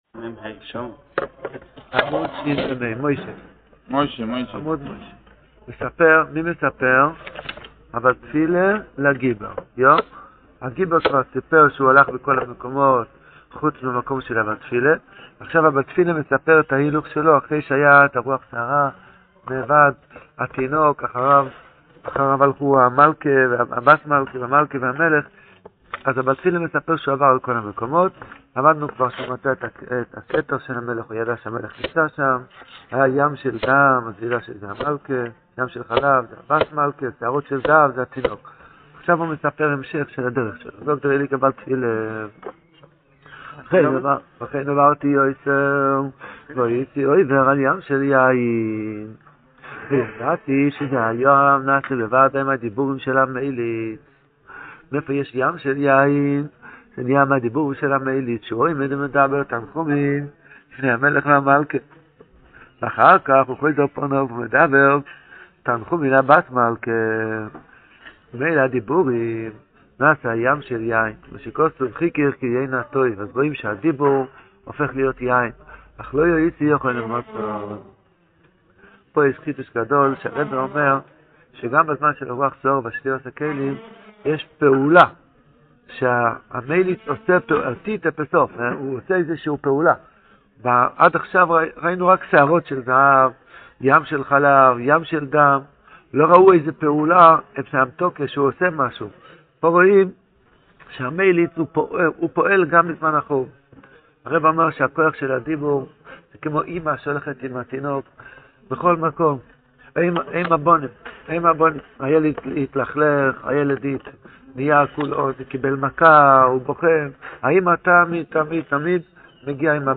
This shiur is given daily after shachris and is going through each of the stories in sipurei maasios in depth. The audio quality gets better after episode 26.